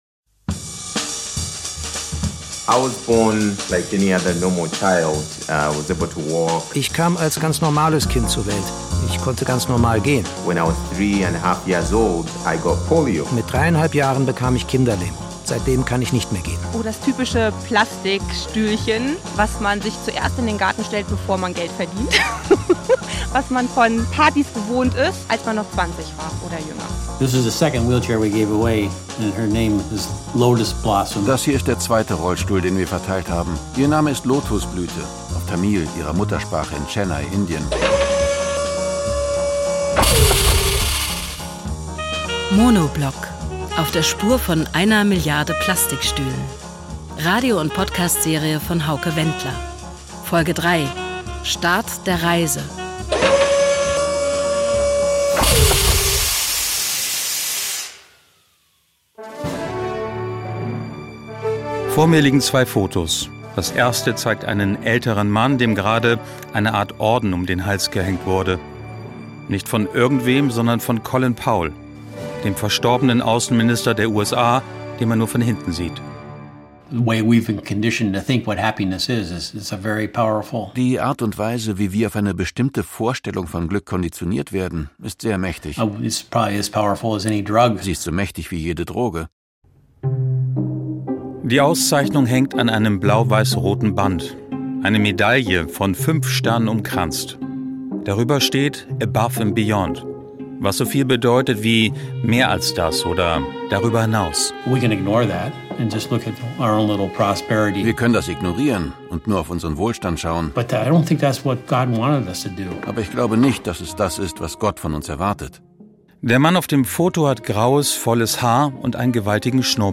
Radio- und Podcast-Serie